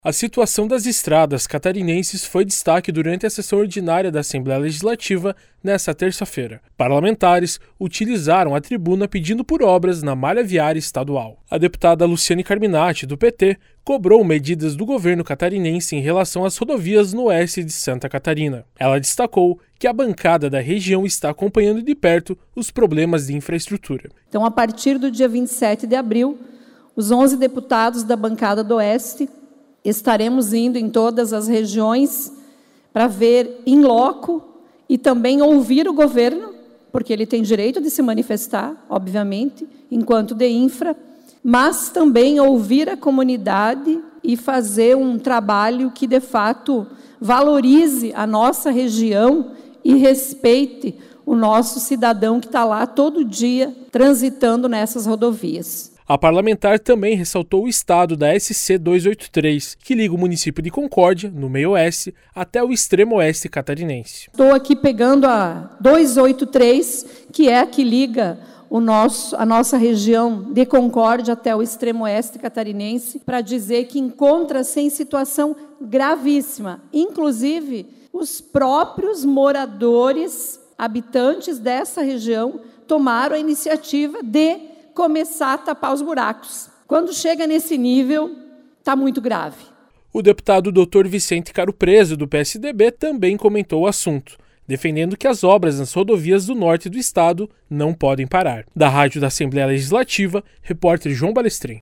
Entrevista com:
- deputada Luciane Carminatti (PT).